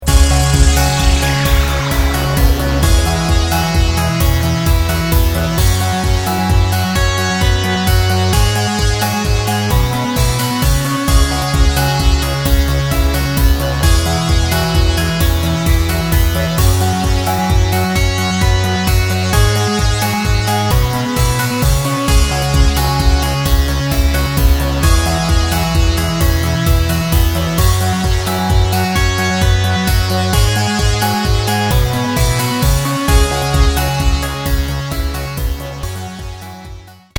クリアな音が僕達の五感を刺激します。浮遊感のファンタジックマジック！！！